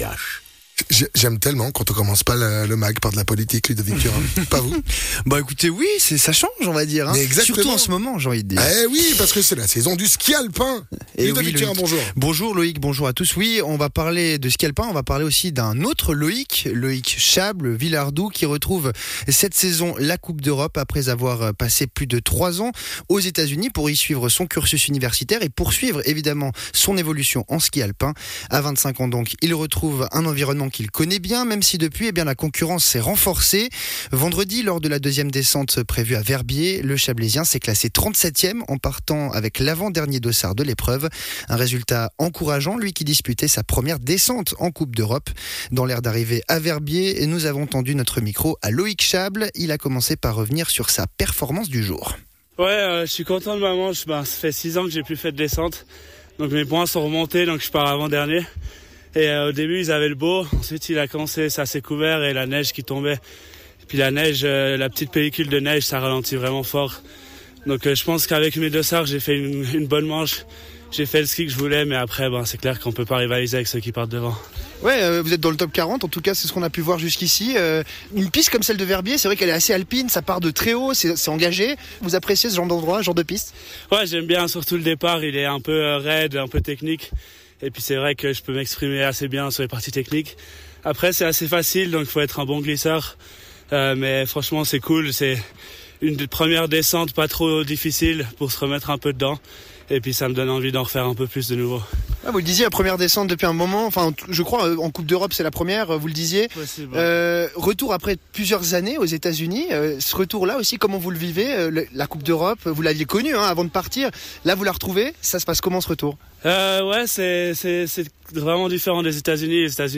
skieur alpin professionnel